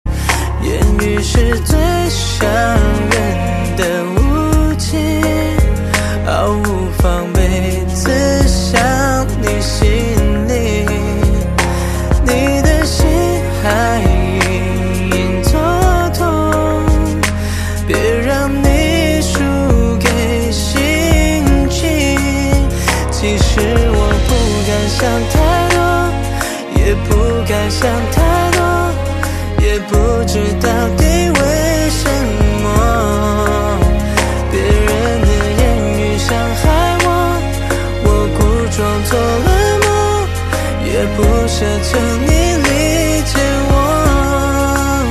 M4R铃声, MP3铃声, 华语歌曲 68 首发日期：2018-05-15 20:52 星期二